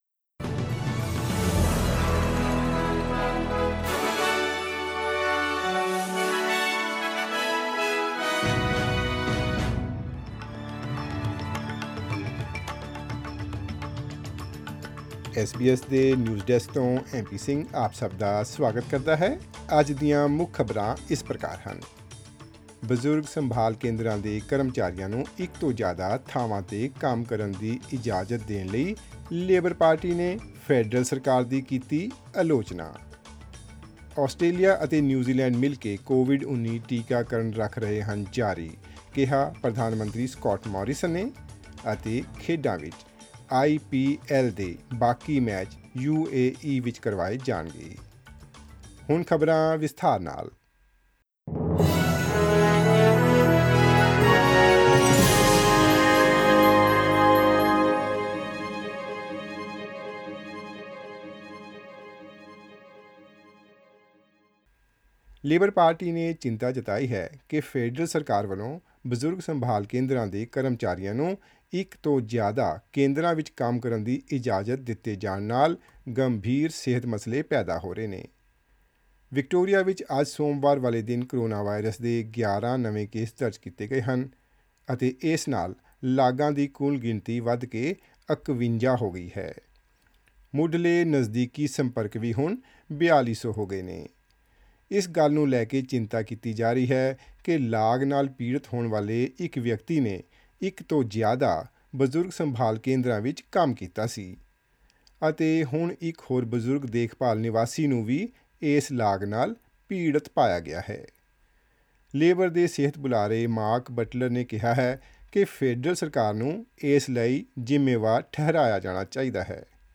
Listen to the latest news in Australia from SBS Punjabi radio.